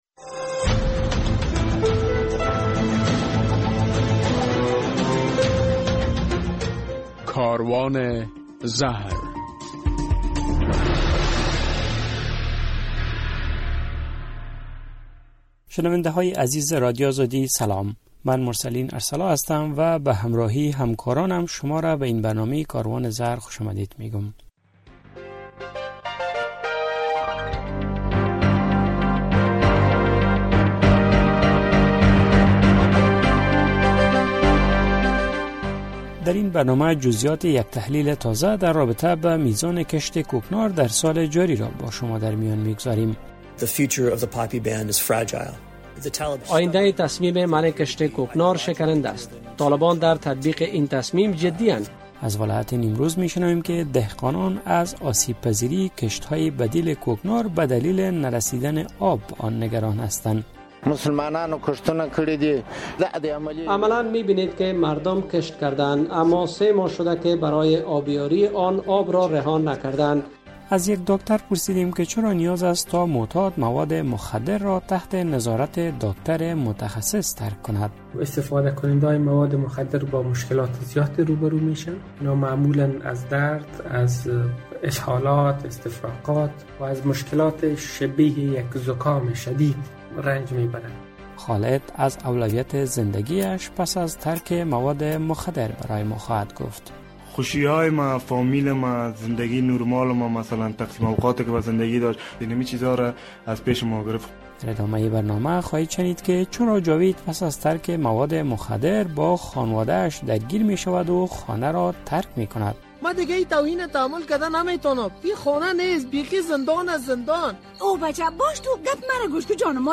در این برنامه کاروان زهر می‌شنوید که یک ارزیابی تازه نشان می‌دهد که امسال در افغانستان بر بیش از هفت هزار هکتار زمین کوکنار کشت شده بود. در یک گزارش از نیمروز می‌شنوید که دهقانان در این ولایت برای آب‌یاری مزارع بدیل کوکنارشان از نبود آب کافی شکایت دارند.